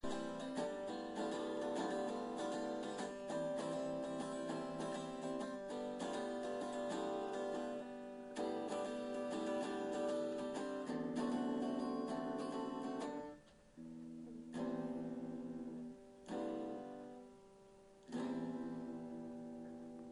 This is my friend playing on her guitar.